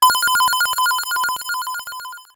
Ringing04.wav